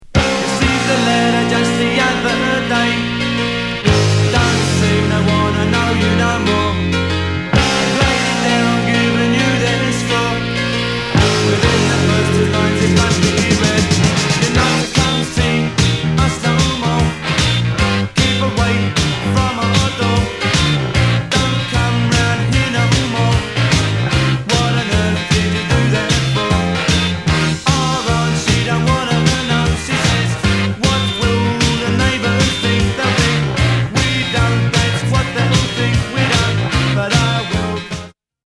80年リリースのダンシング憂鬱(?)ナムバー！！